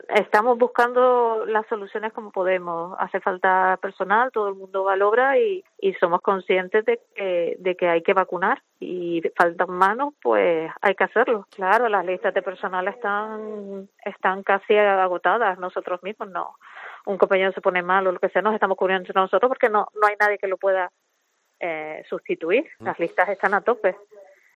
una enfermera de un centro de salud de Gran Canaria